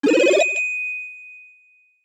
Sound effect of Super Bell Sprout in Super Mario 3D World.